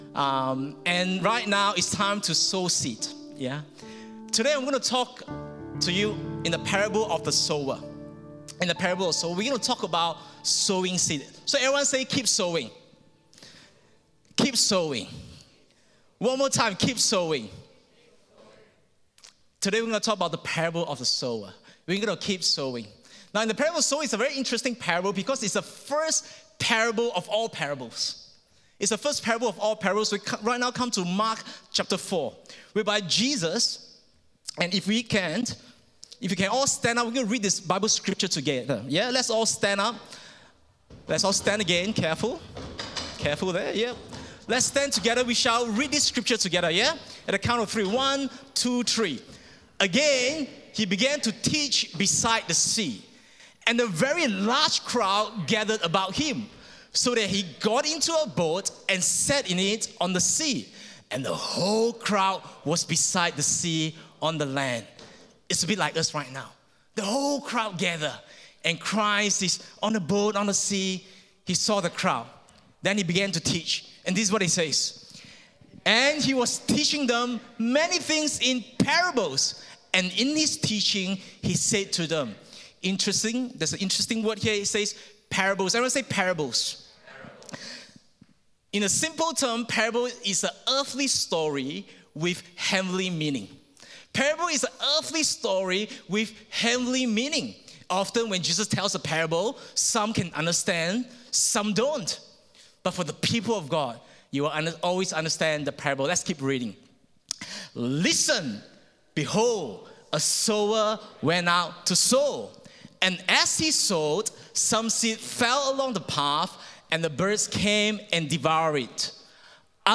English Sermons | Casey Life International Church (CLIC)
English Worship Service - 28th August 2022